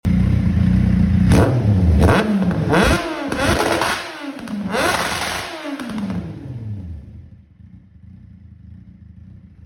Angry Kawasaki Z750 shooting flames sound effects free download